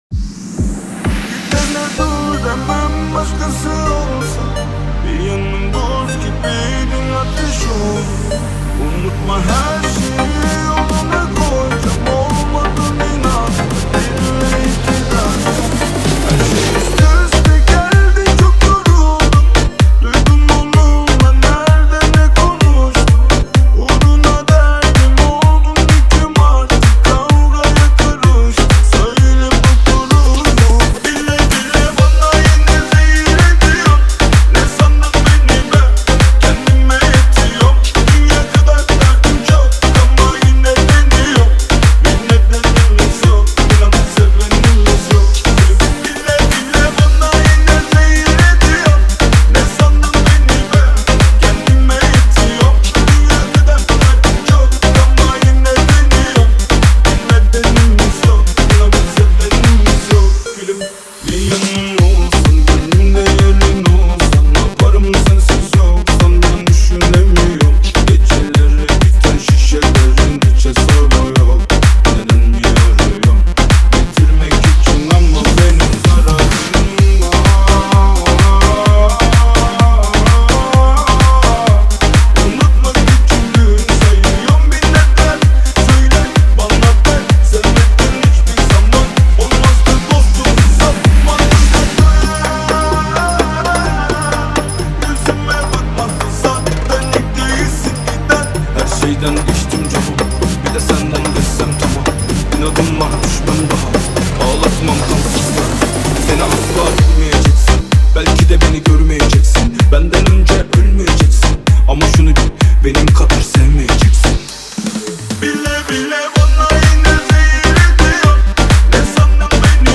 دانلود نسخه ریمیکس همین موزیک